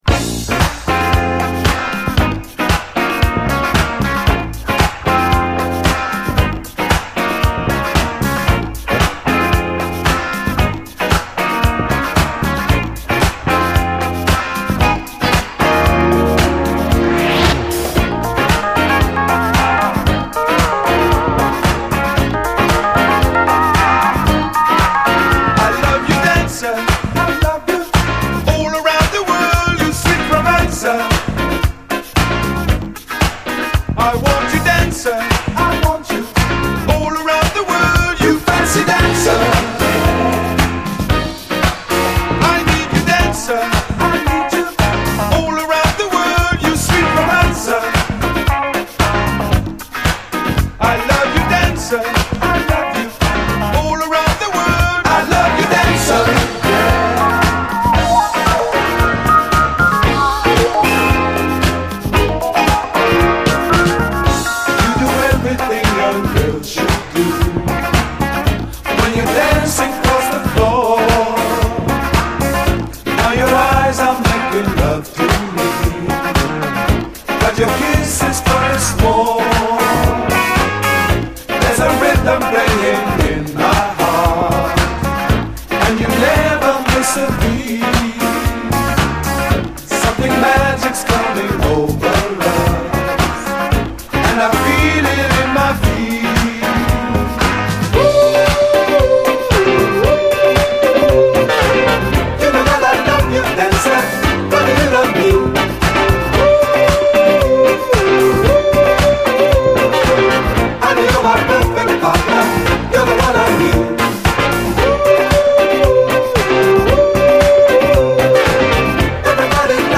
鳴りも完璧です。